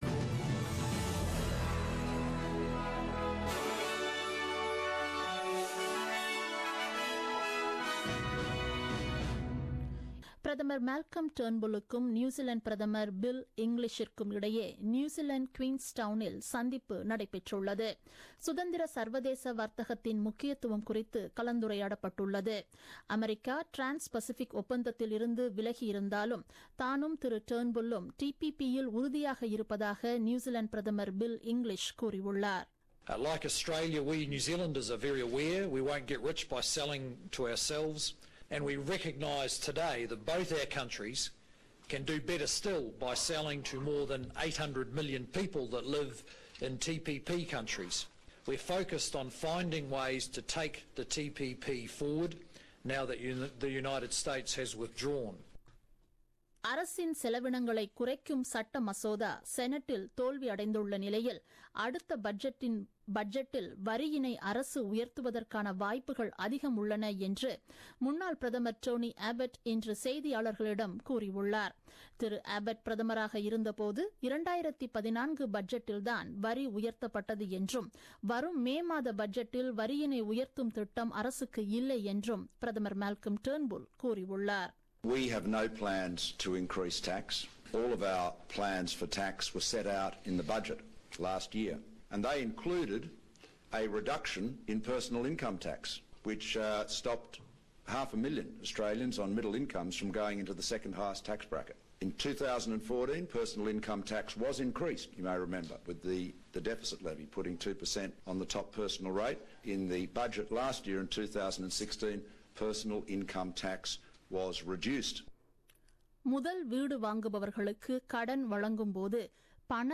நமது SBS தமிழ் ஒலிபரப்பில் இன்று வெள்ளிக்கிழமை (17 பிப்ரவரி 2017) இரவு 8 மணிக்கு ஒலித்த ஆஸ்திரேலியா குறித்த செய்திகள்.